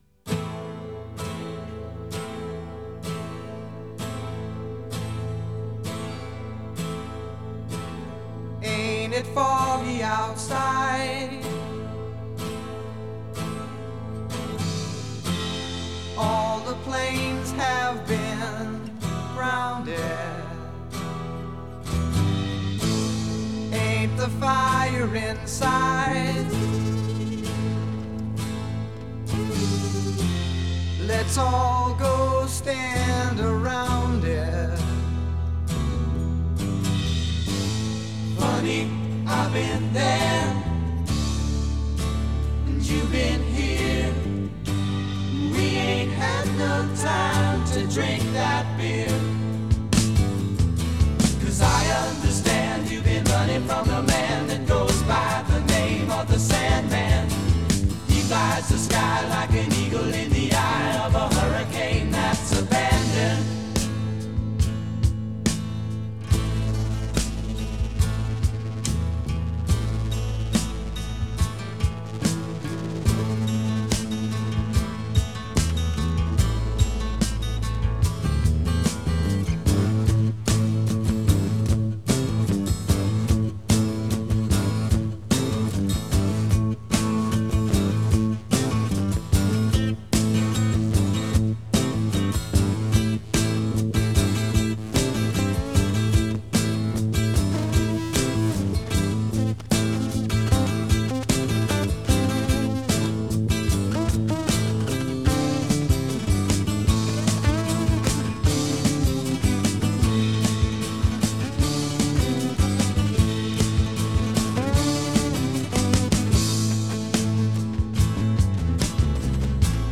Genre: Folk-Rock.